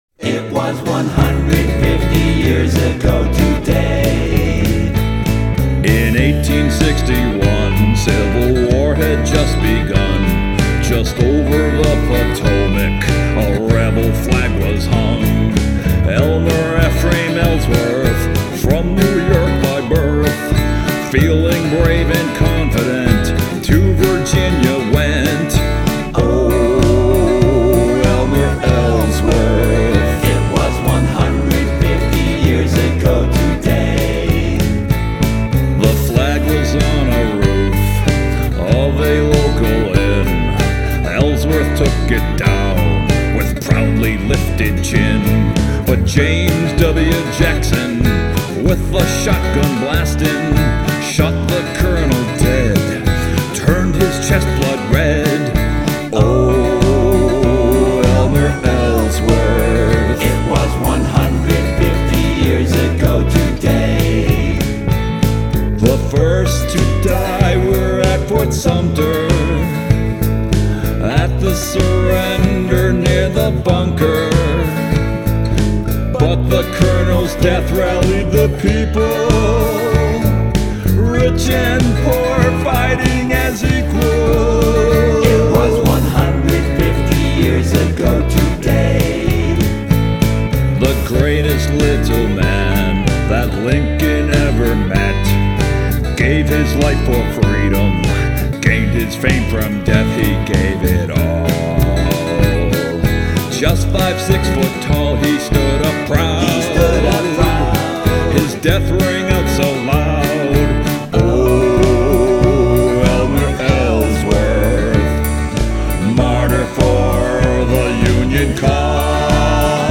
And it's catchy and upbeat and fun!